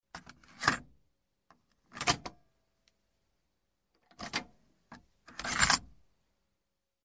На этой странице собраны звуки магнитофона: шум ленты, щелчки кнопок, запись с кассет.